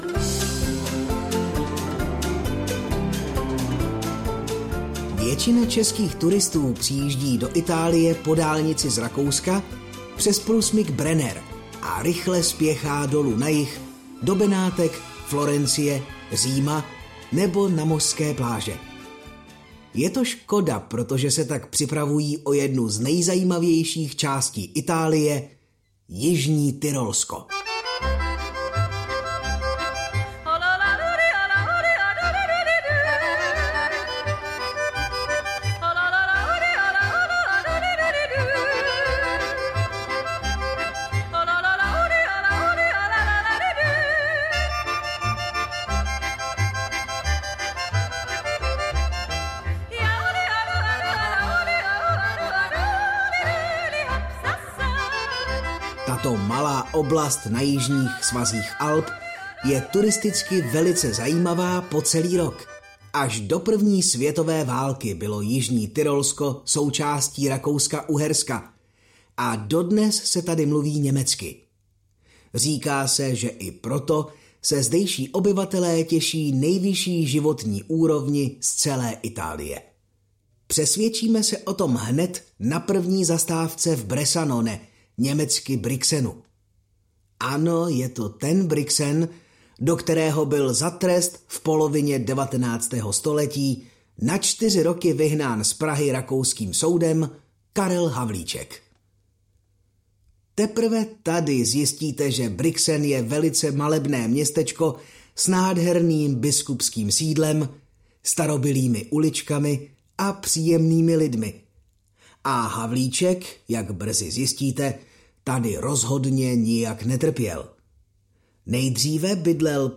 Ukázka z knihy
Nejznámějšími místy v Itálii nás provede zvuková nahrávka s originální zvukovou i hudební kulisou. Poutavé vyprávění plné zajímavých informací podbarvují autentické zvuky prostředí.